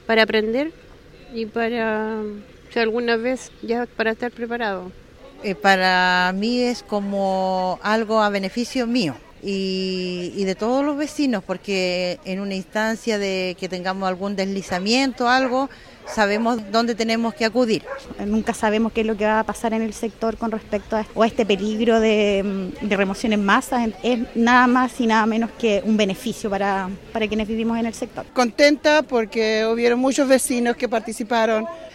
Ante ello, Radio Bío Bío conversó con vecinos que participaron de la evacuación, quienes valoraron positivamente que se realicen este tipo de actividades, sobre todo para conocer qué se debe hacer ante una emergencia real.